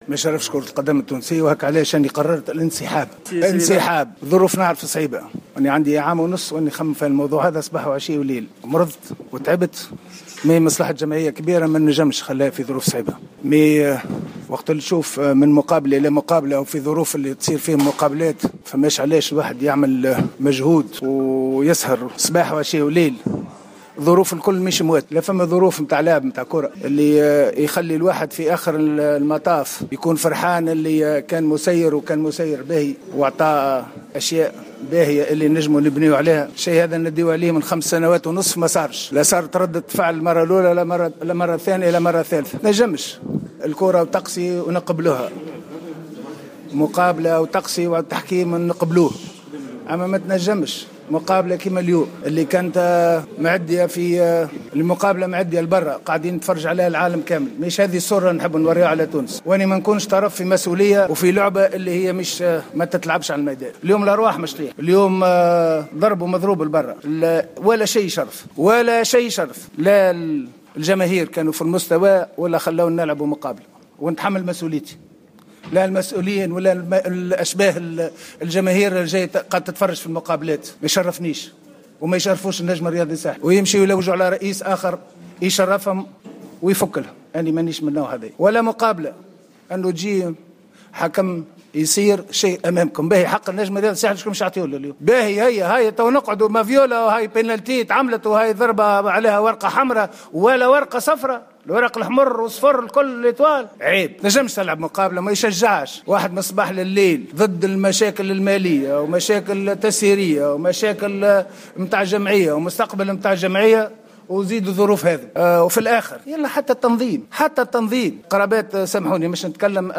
أكد رئيس النجم الساحلي الدكتور رضا شرف الدين في تصريح إثر نهاية مواجهة الترجي الرياضي التونسي أنه قد قرر الإنسحاب من الفريق إثر الأحداث التي رافقت المقابلة .